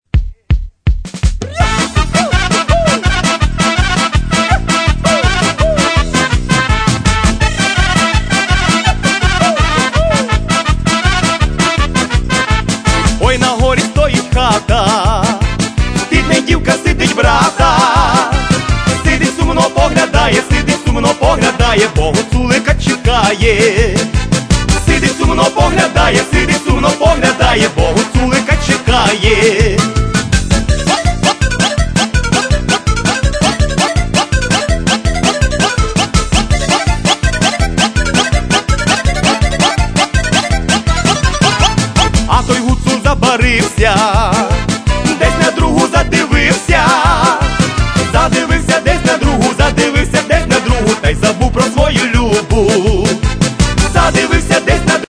Collection of the Best Ukrainian Zabava Songs